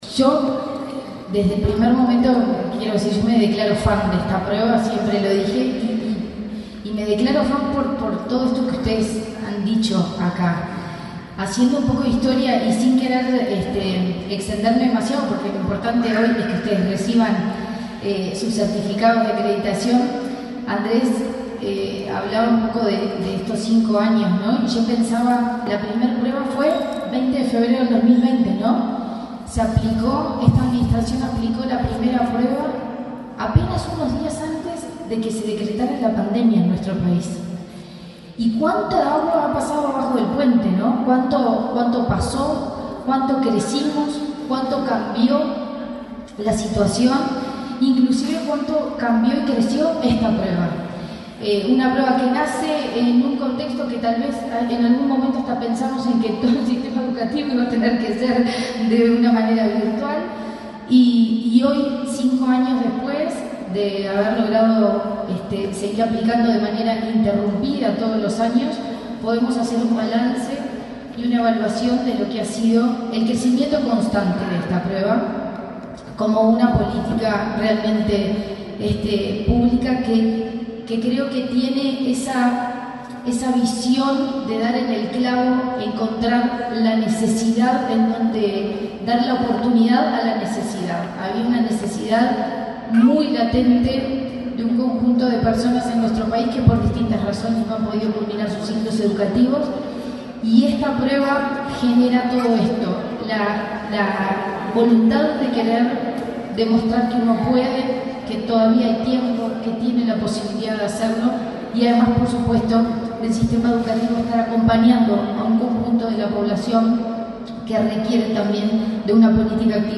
Palabras de la presidenta de ANEP, Virginia Cáceres
Este miércoles 6 en Montevideo, la presidenta de la Administración Nacional de Educación Pública (ANEP), Virginia Cáceres, participó de la